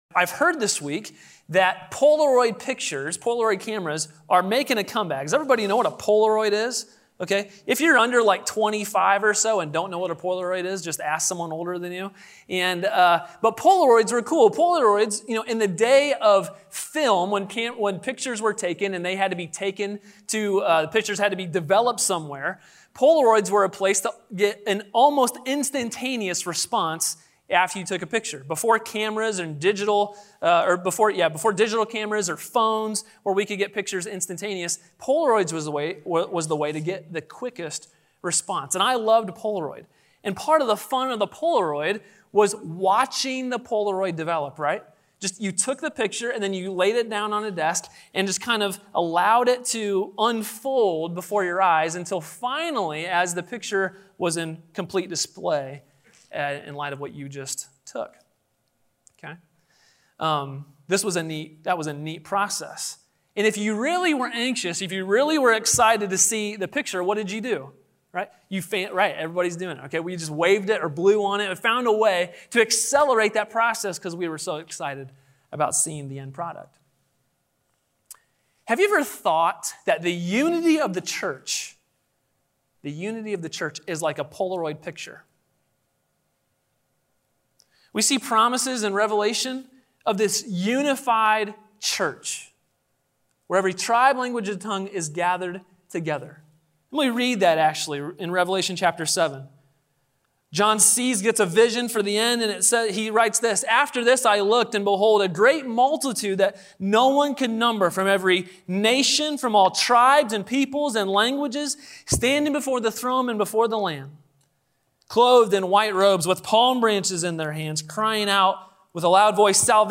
A sermon from the series "Living Out the Gospel." The Gospel is the power of God for the salvation of individuals, the formation of a people, and the restoration of the cosmos.